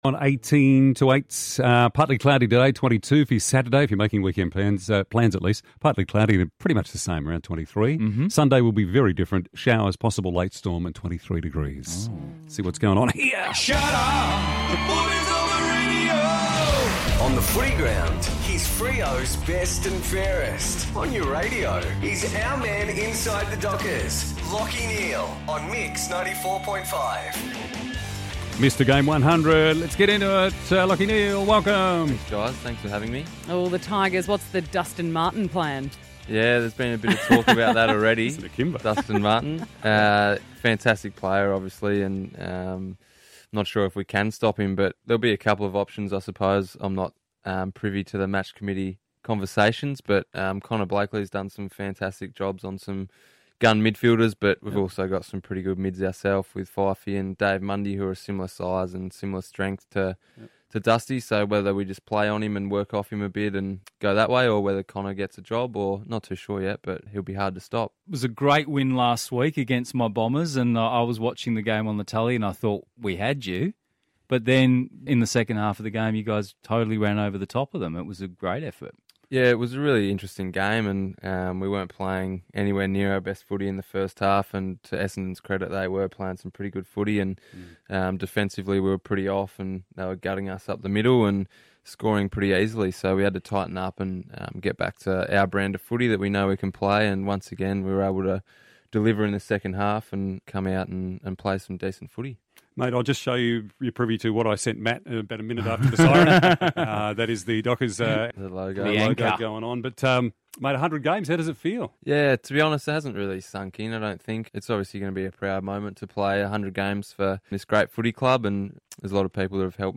Lachie Neale chats to the team at 94.5 ahead of his 100th AFL game.